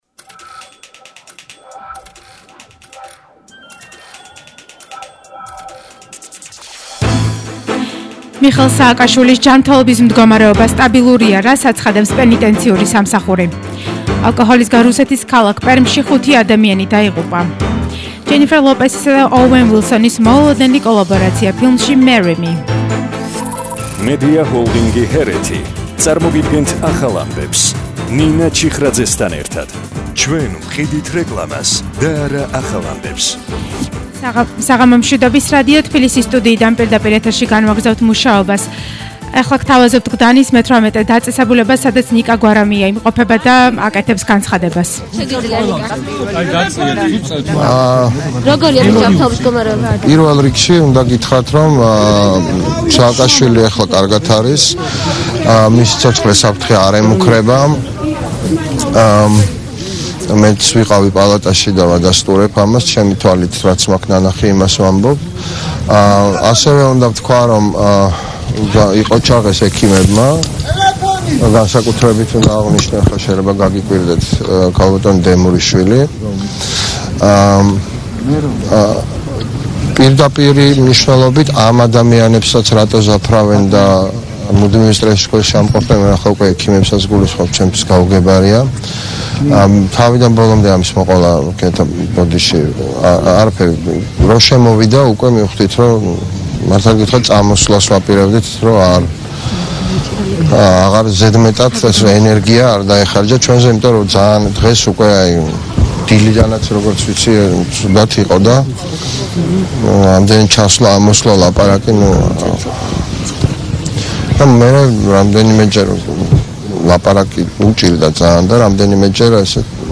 ახალი ამბები 20:00 საათზე –18/11/21